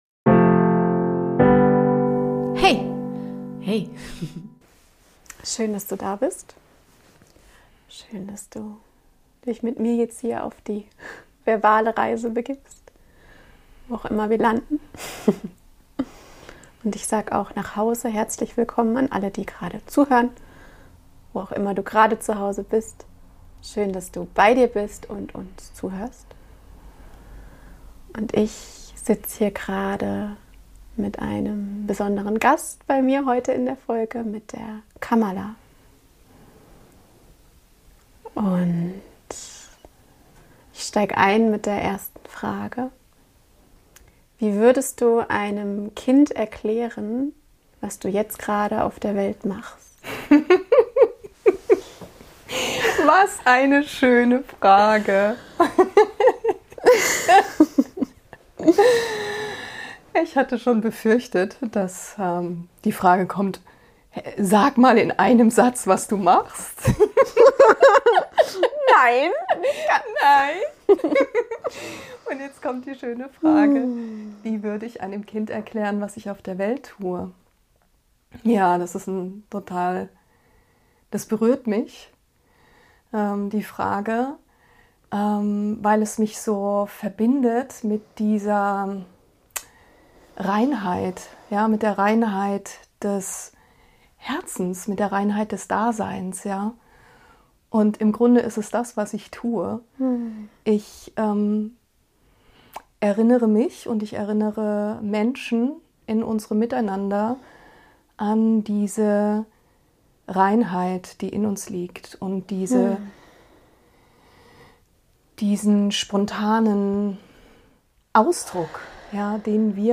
Ein ganz persönliches, gegenwärtiges Gespräch zwischen zwei Frauen, die sich selbst gerne erforschen, um ihr Leben immer freier, immer wacher, immer wahrhaftiger zu gestalten.